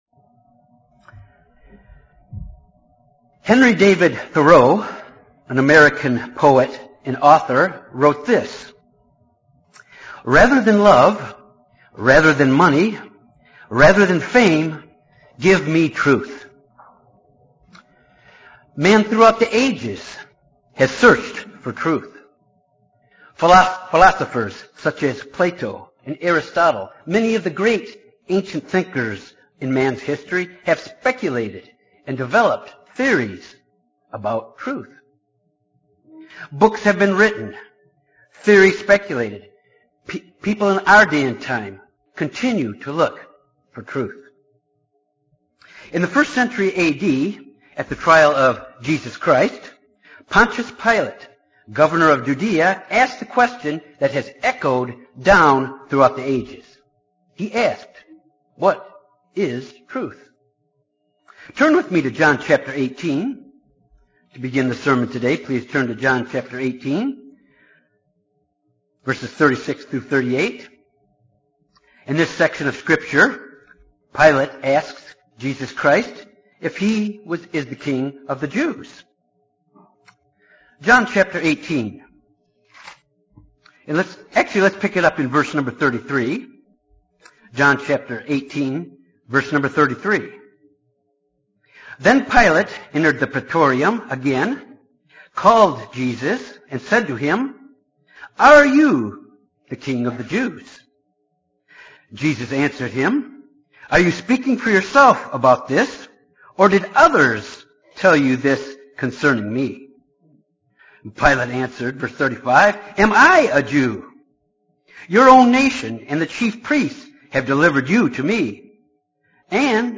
Millions read the bible, yet don’t know the truth. This sermon examines the reasons we should be glad to know the truth.